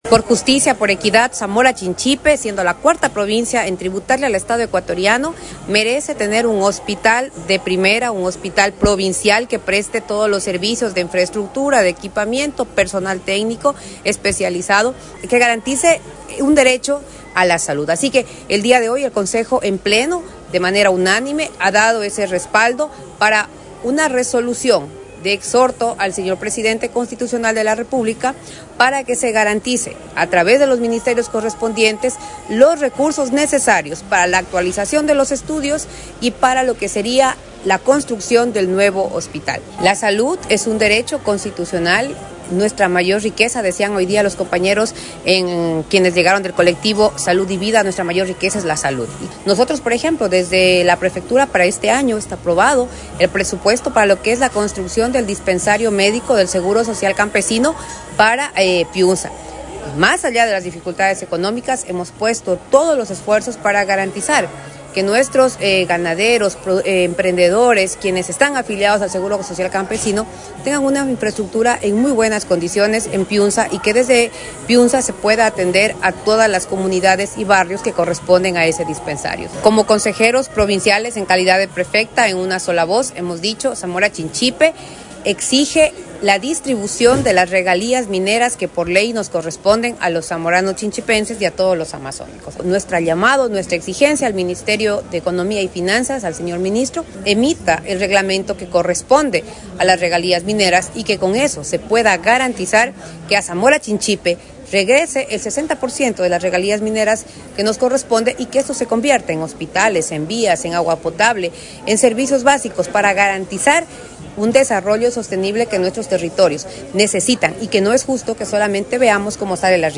KARLA REÁTEGUI, PREFECTA
KARLA-REATEGUI-PREFECTA.mp3